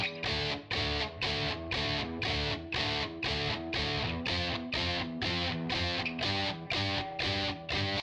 1 channel